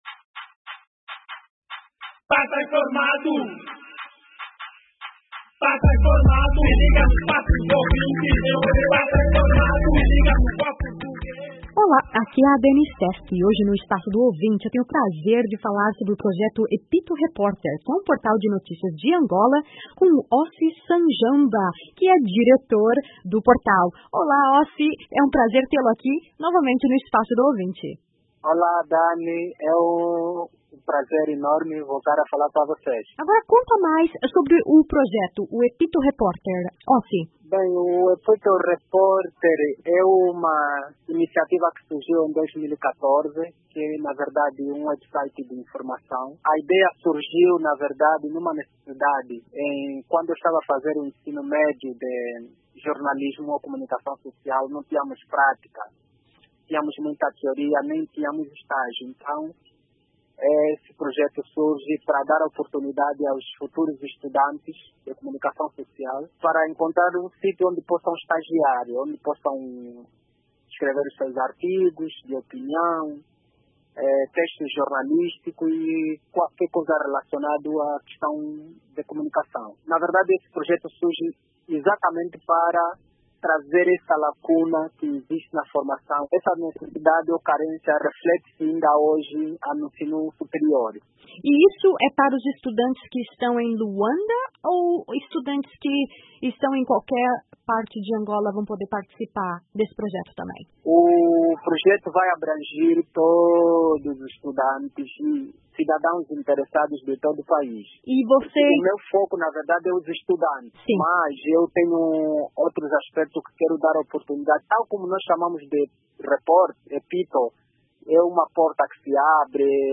Embed Entrevista de áudio